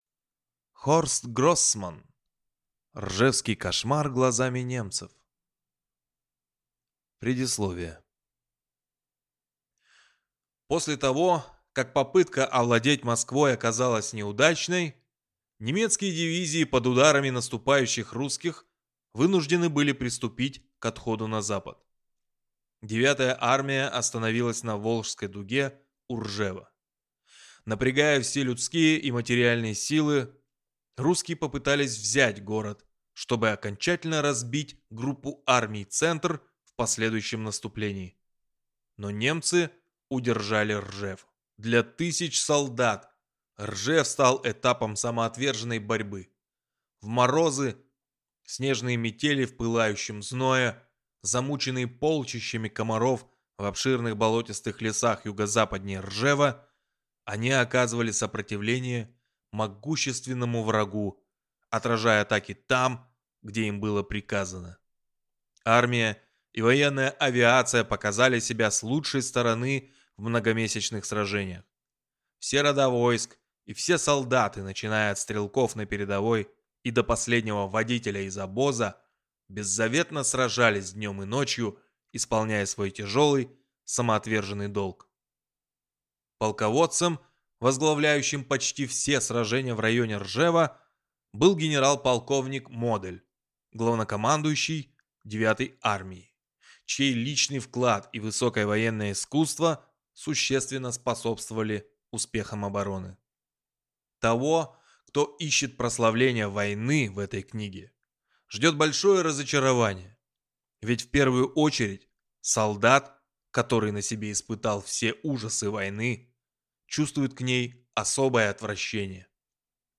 Аудиокнига Ржевский кошмар глазами немцев | Библиотека аудиокниг